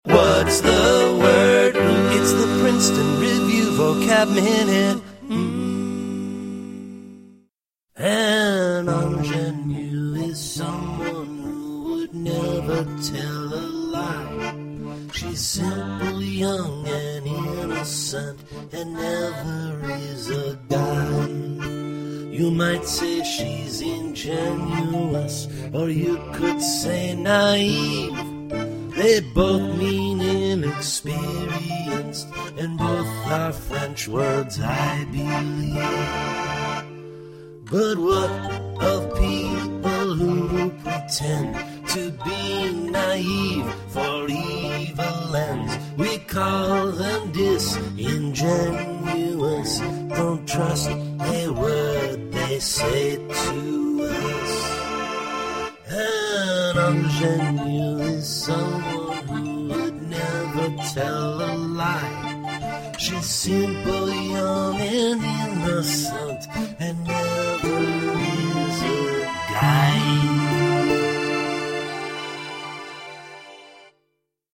with catchy tunes and witty verse